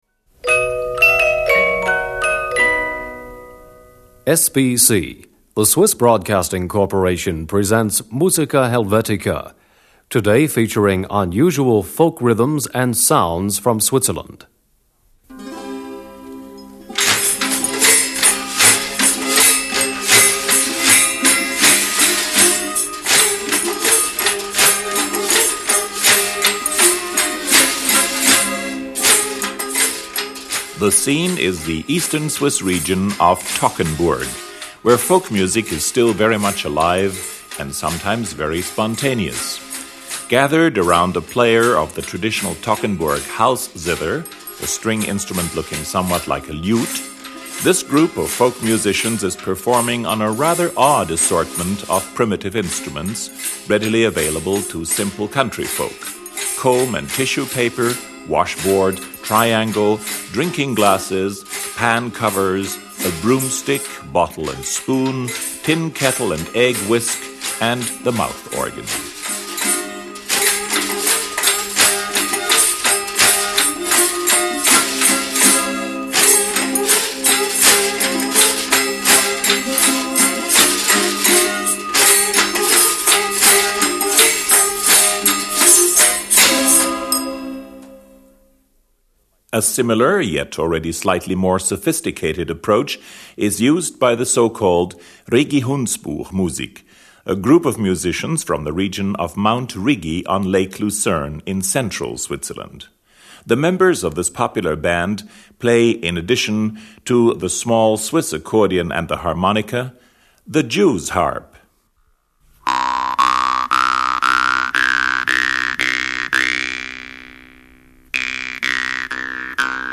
They are produced on instruments both simple and sophisticated: from sticks, spoons, cowbells, and jew’s harp to traditional string instruments, the mighty Alphorn and including the splendour of the human voice.
Some of the most unusual folk rhythms and sounds from Switzerland can be heard in this recording.